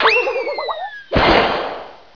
Cartoon action